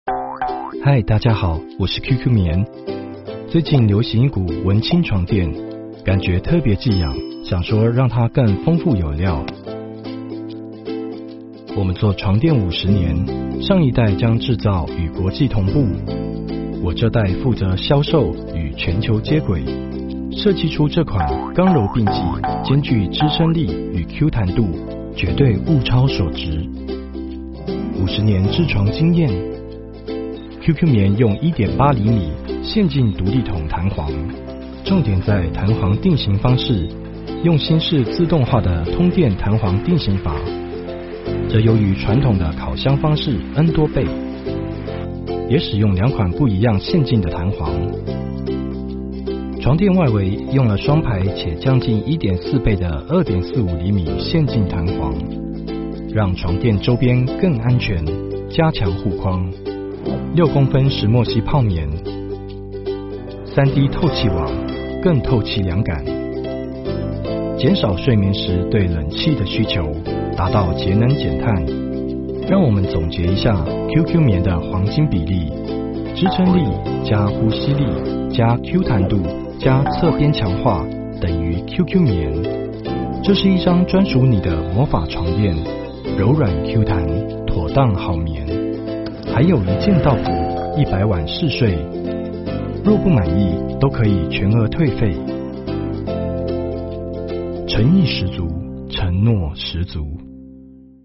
• 10台湾男声4号
动画解说-亲和舒耳